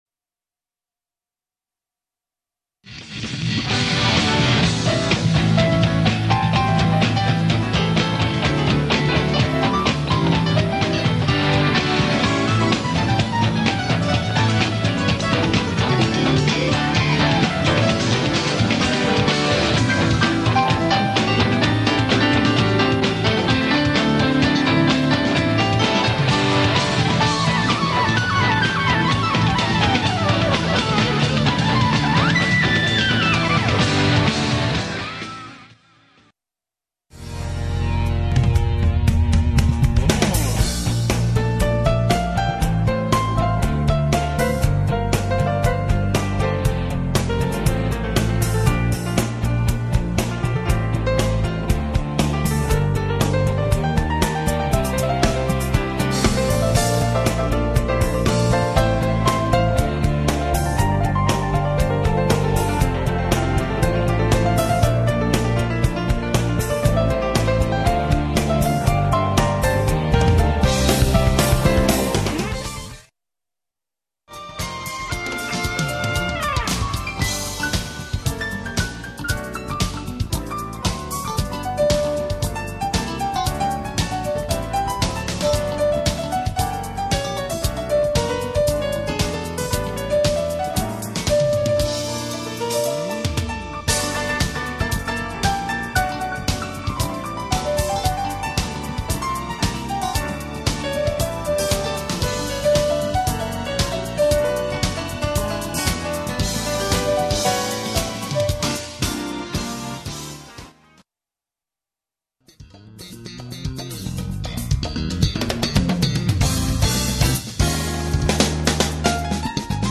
키보드중에 애드립이나 멜로디가 좋은 파트만 모아서 편집해봤습니다